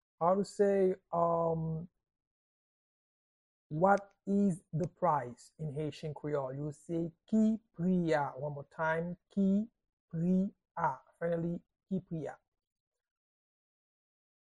Pronunciation and Transcript:
How-to-say-What-is-the-price-in-Haitian-Creole-–-Ki-pri-a-pronunciation-by-a-Haitian-speaker.mp3